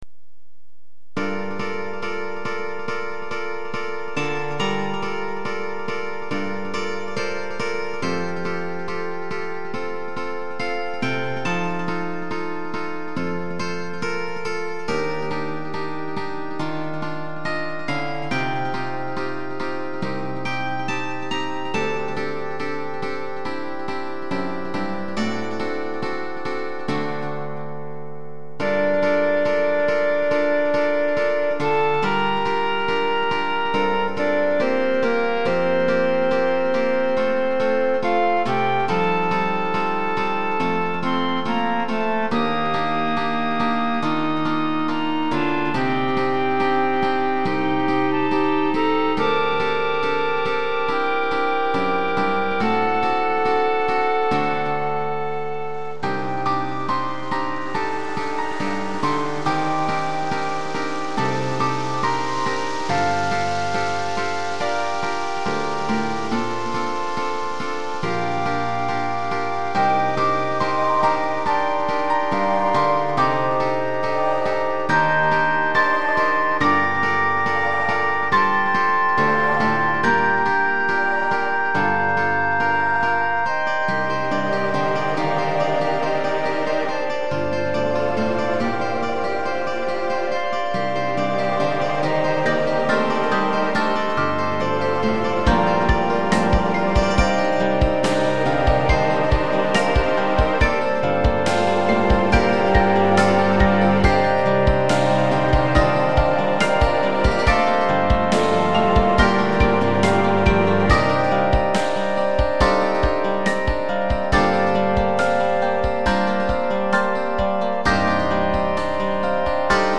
作品表示 Melancholy 憂鬱と不安感。
インストゥルメンタル